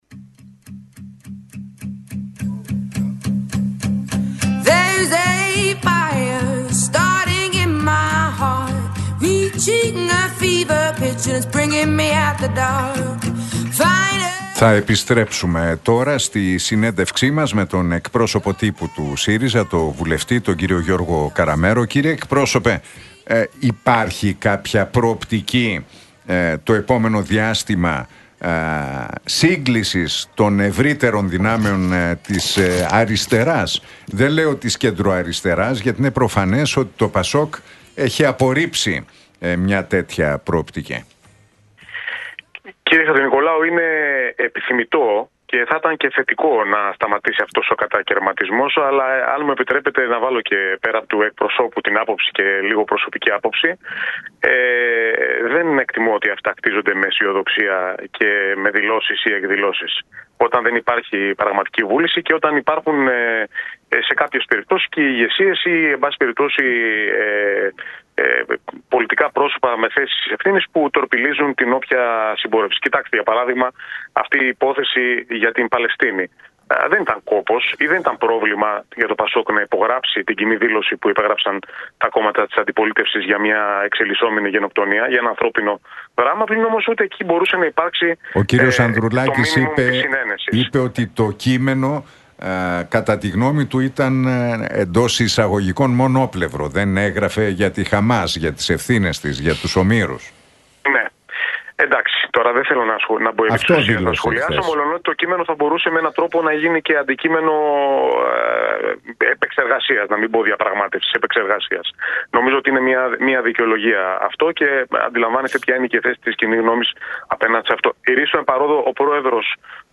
Ακούστε την εκπομπή του Νίκου Χατζηνικολάου στον ραδιοφωνικό σταθμό RealFm 97,8, την Δευτέρα 26 Μαΐου 2025.